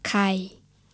This word begins with "khor khai".
khai low tone.